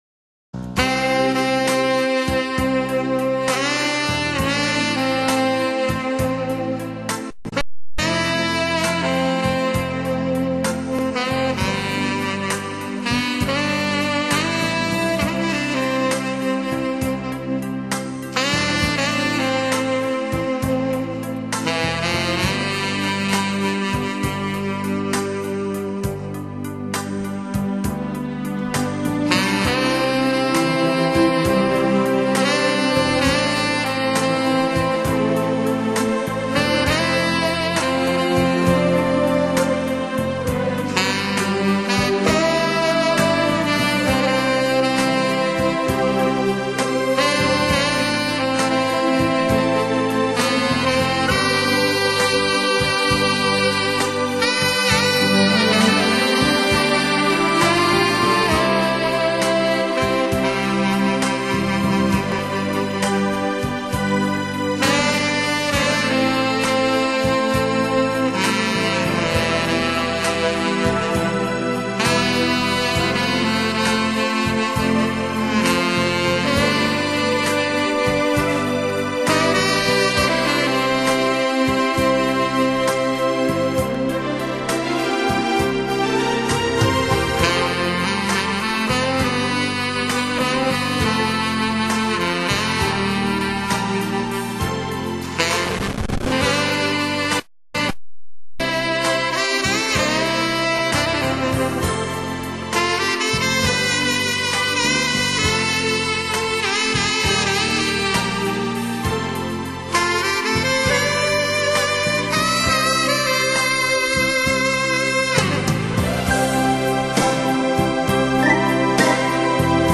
[原创]经典名曲----萨克斯风